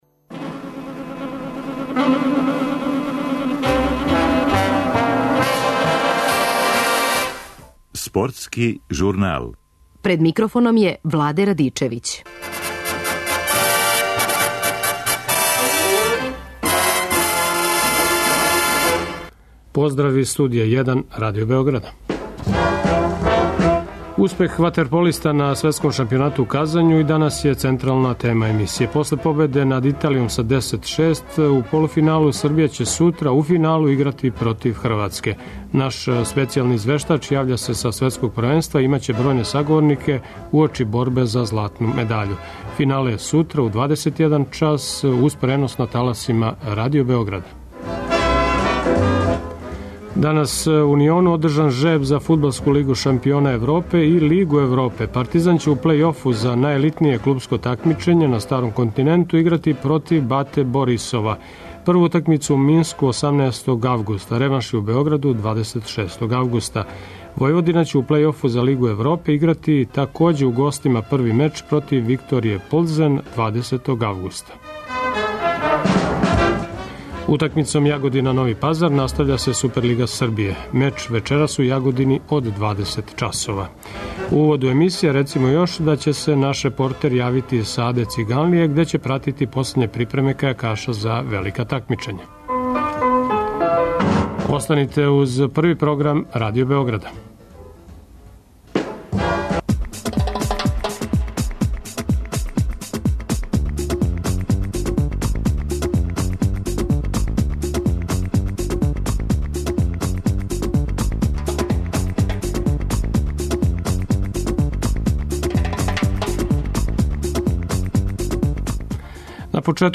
Наш специјални извештач јавља се са Светског првенства и имаће бројне саговорнике уочи борбе за златну медаљу.